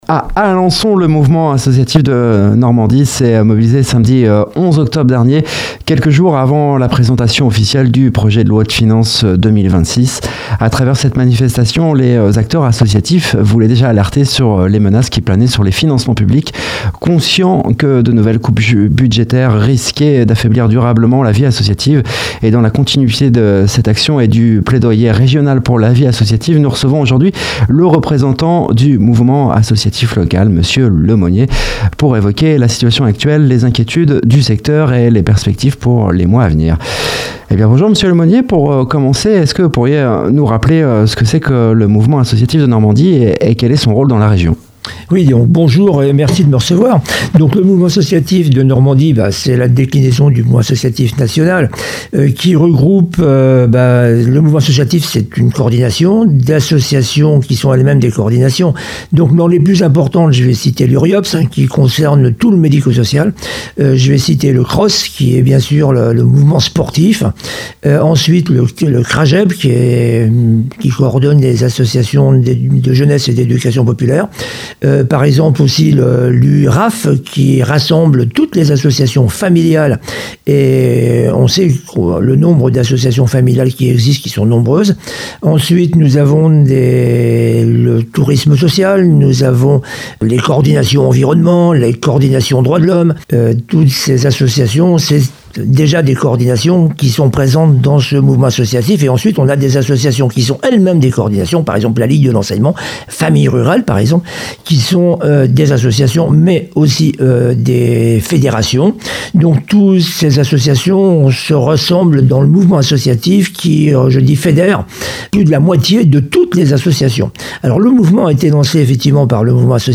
L’entretien évoque également le plaidoyer régional pour la vie associative en Normandie, les difficultés rencontrées sur le terrain, mais aussi les pistes de mobilisation et les initiatives porteuses d’espoir dans notre région.